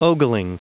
Prononciation du mot ogling en anglais (fichier audio)